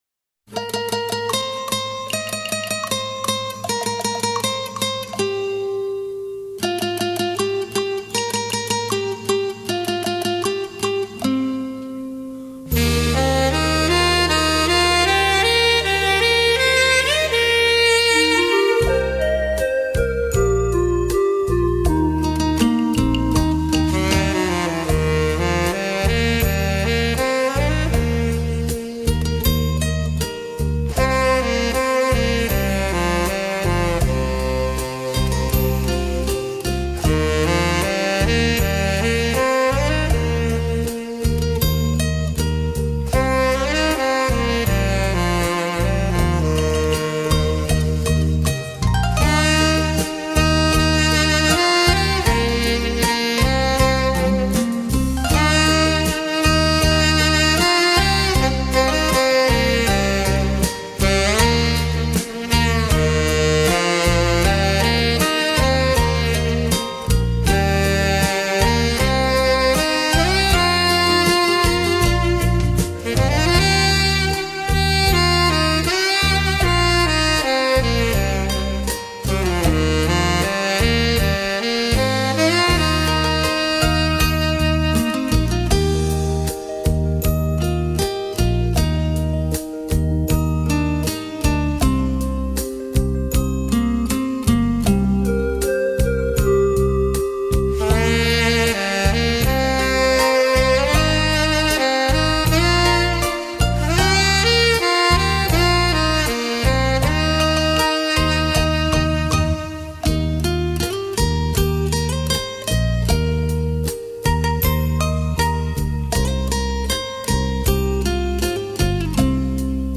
感性浪漫的悠扬音乐